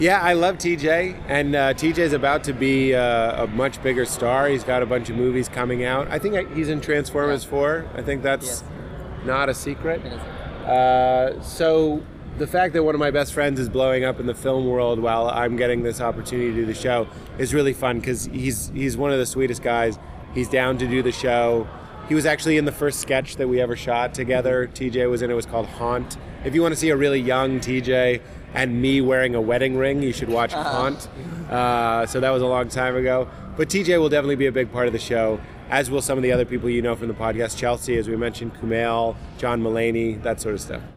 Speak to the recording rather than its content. While audio of the Q&A was recorded, most of the questions coming in were too far from the microphone to be heard well, so you can read each question below and then hear the response given by the panel.